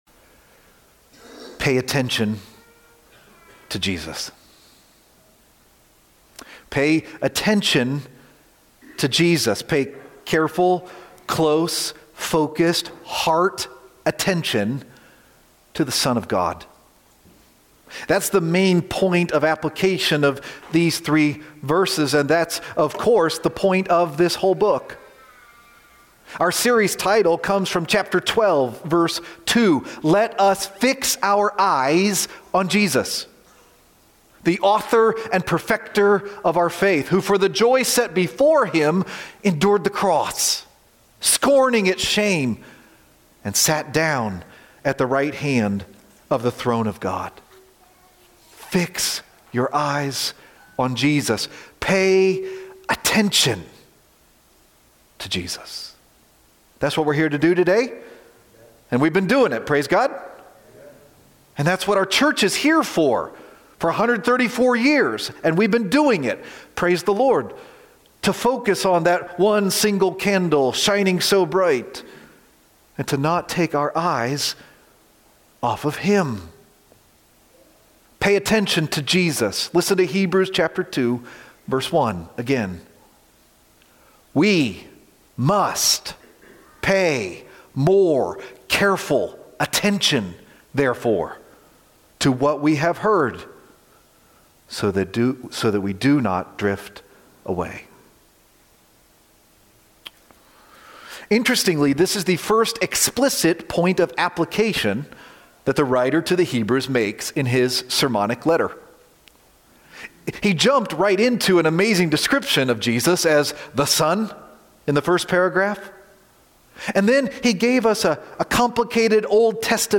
Such a Great Salvation :: February 15, 2026 - Lanse Free Church :: Lanse, PA